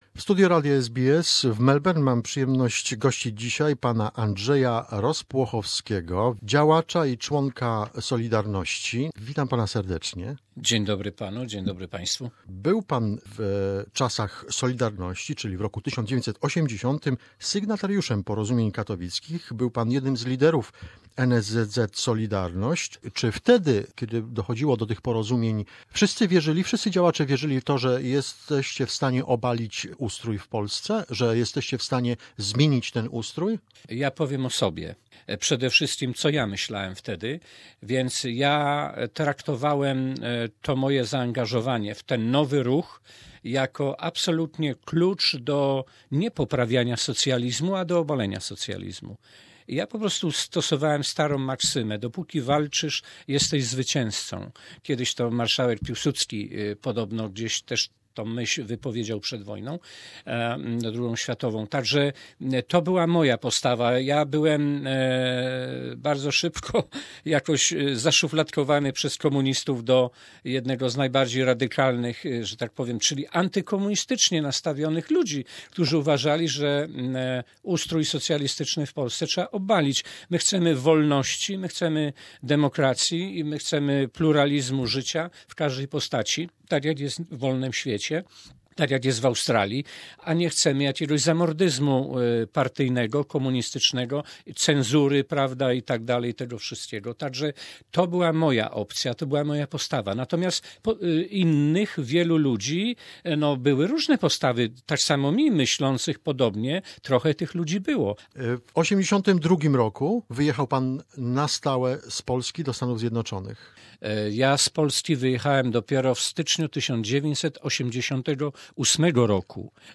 This is a part 2 of the interview.